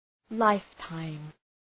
Shkrimi fonetik {‘laıftaım}